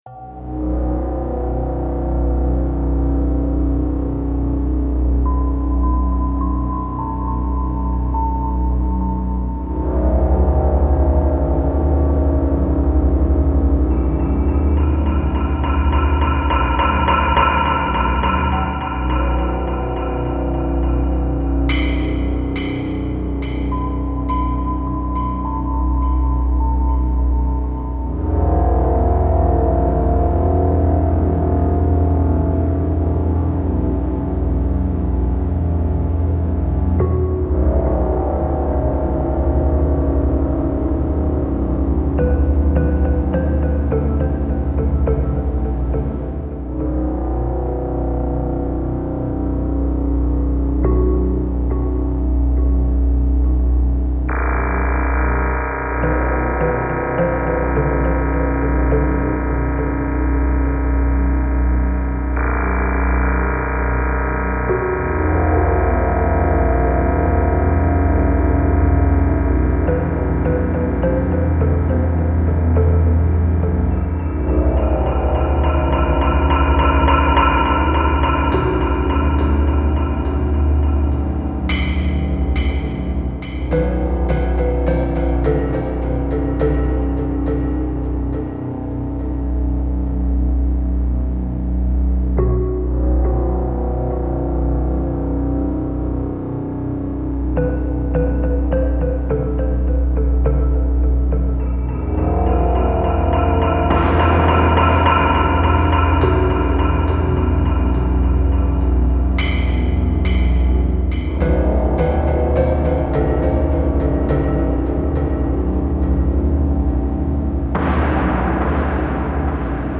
Genre: Dark Ambient, Drone.